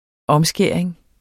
Udtale [ -ˌsgεˀɐ̯eŋ ]